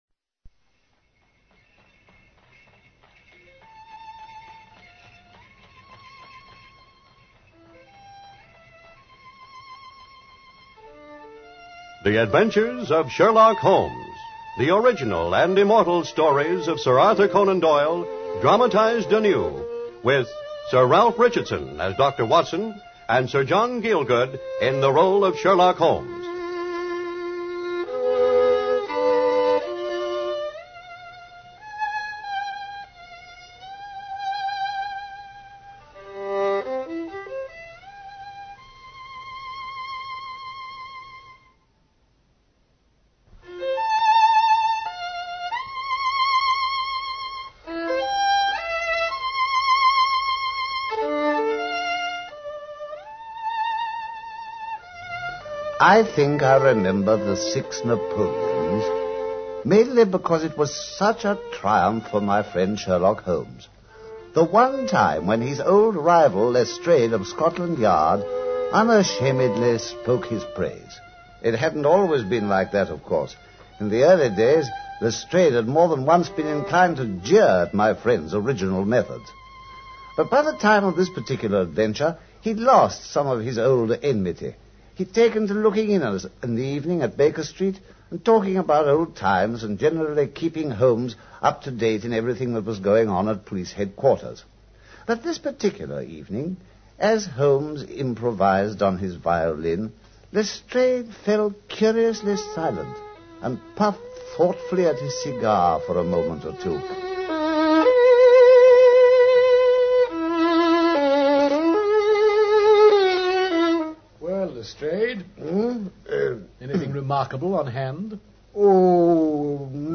Radio Show Drama with Sherlock Holmes - The Six Napoleons 1954